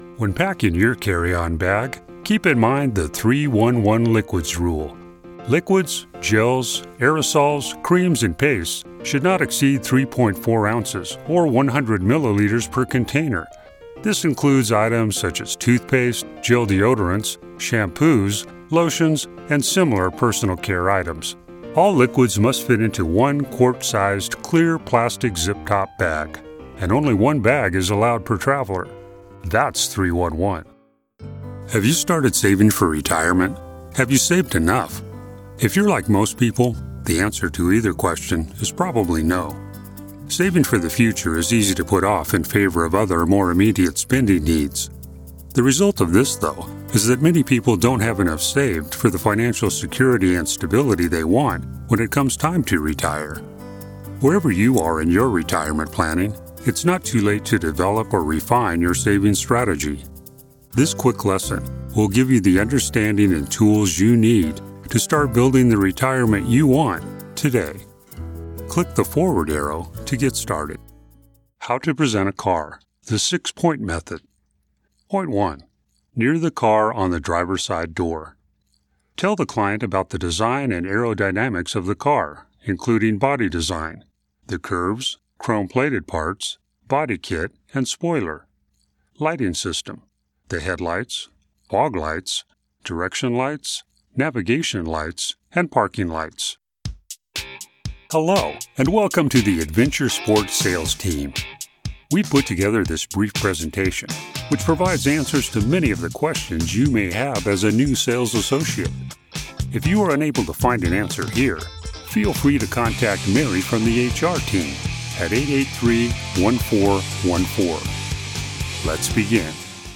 Mature Adult, Adult
Has Own Studio
standard us
e-learning
friendly
reassuring
trustworthy
warm